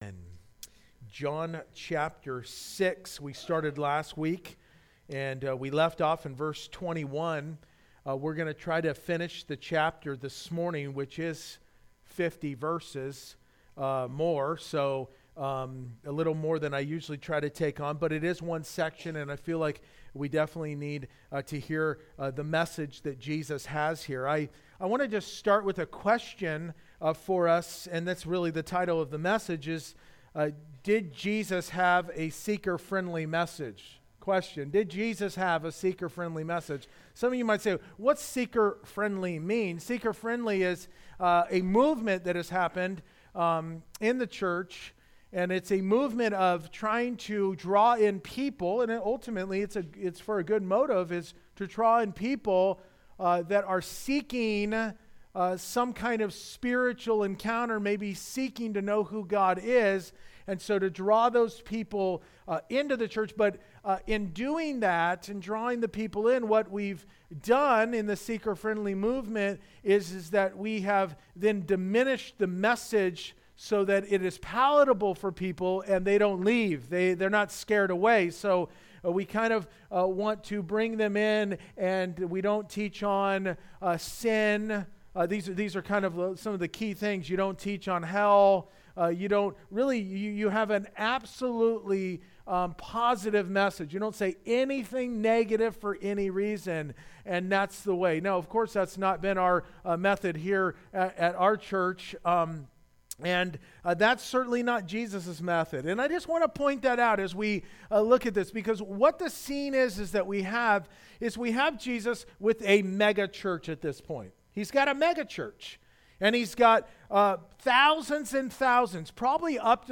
Verse by Verse-In Depth « So Many Opinions–Who Is Right?